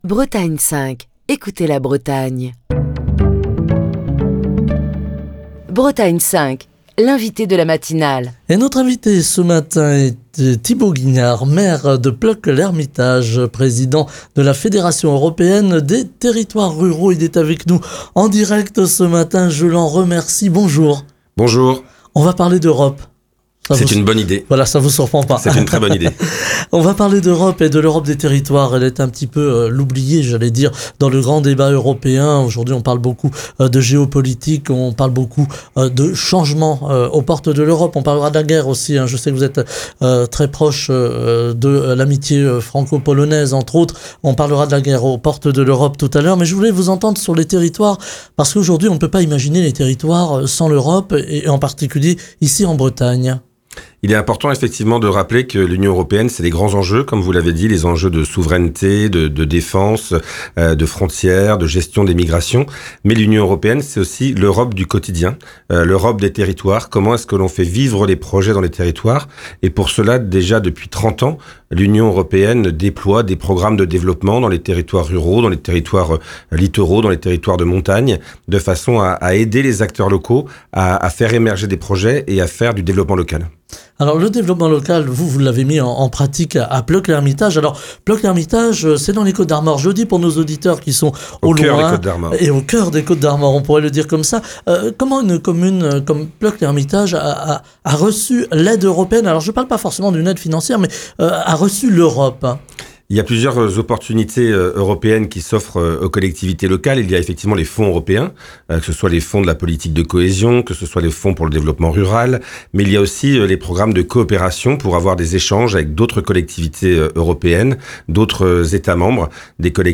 Souvent en évoquant l'Europe, on pense grands enjeux internationaux, politique de défense, économie, plus rarement il est question de l'impact des décisions de l'Union européenne sur les territoires. Ce matin, précisément nous nous penchons sur l'Europe des territoires, et particulièrement des territoires ruraux avec Thibaut Guignard, président de la Fédération européenne des territoires ruraux, maire de Plœuc-L’Hermitage dans les Côtes d'Armor, qui est l'invité de la matinale de Bretagne 5.